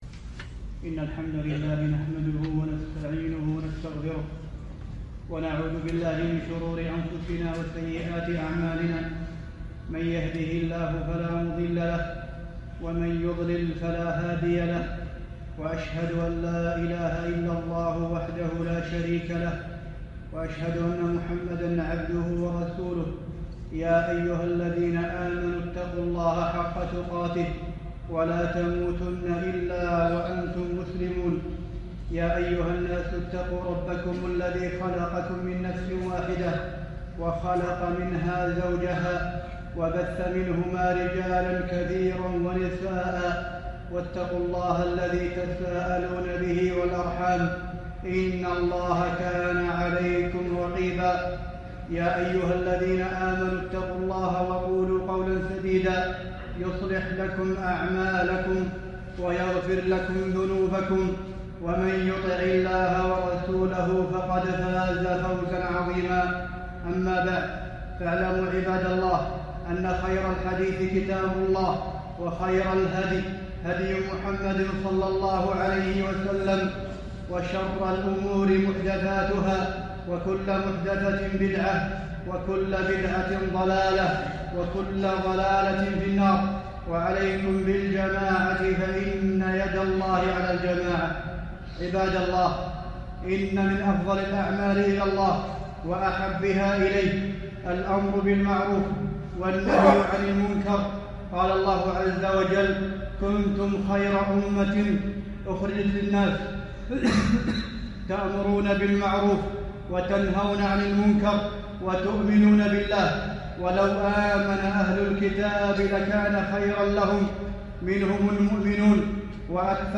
وجوب الأمر بالمعروف - خطبة